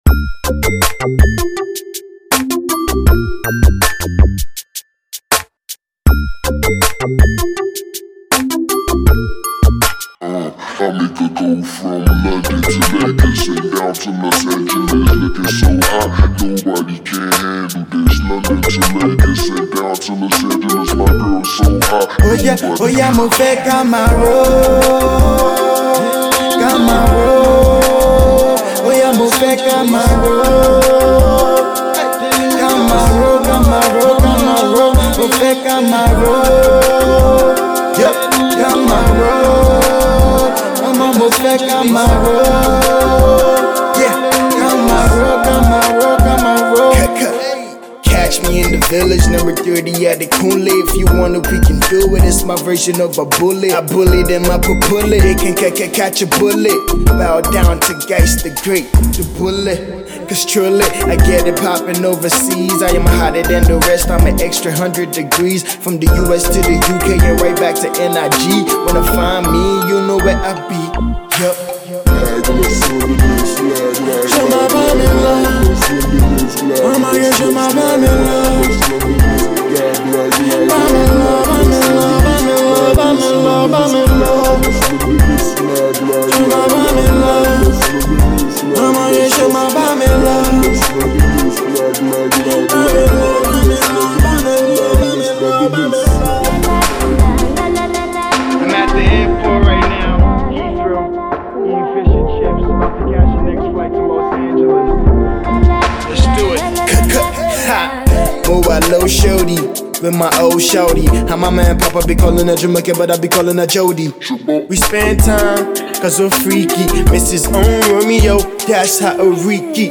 hip hop
screwed track